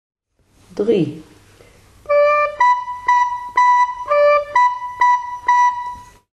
Opa’s notenboom en een team van 4 zingende cachers leidt tot een puzzel, die om noten draait.
Ieder fragmentje is het begin van een, nog steeds bekend Nederlandstalig kinderliedje uit de oude doos.